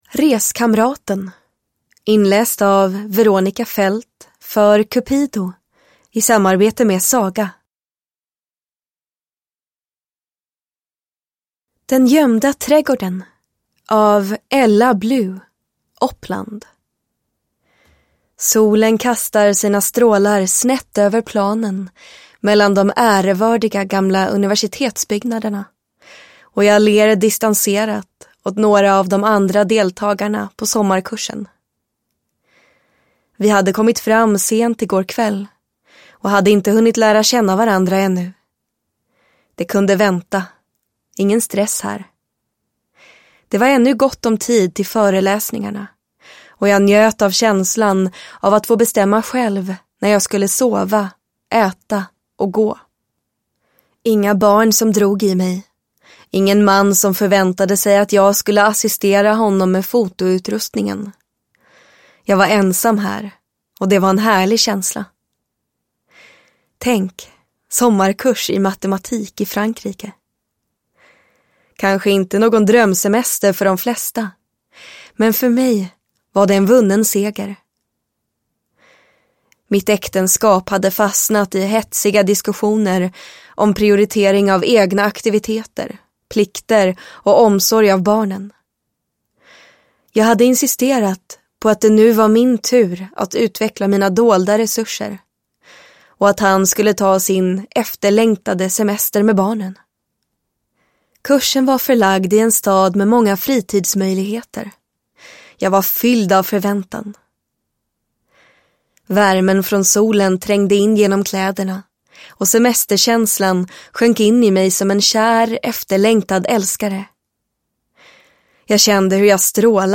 Reskamraten (ljudbok) av Cupido